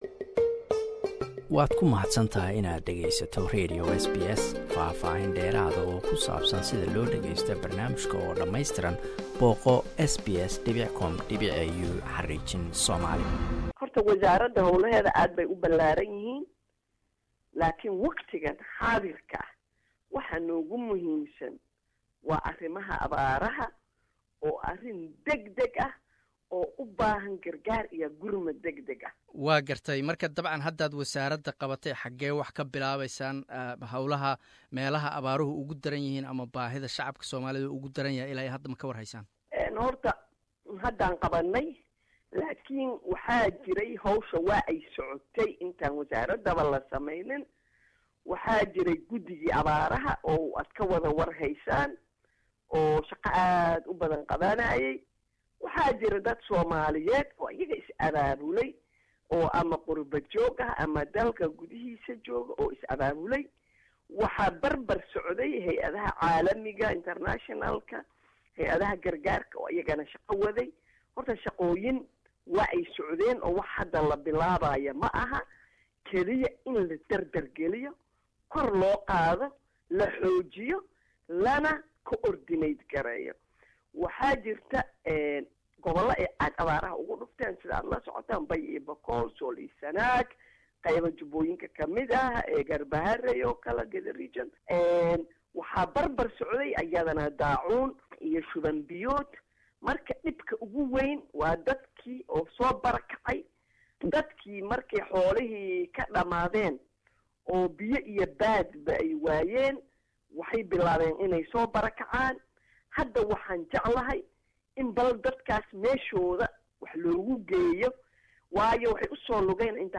Waraysi: Dr Maryan Qaasin,Wasiirada arimaha binaadanimada iyo maraynta masiibooyinka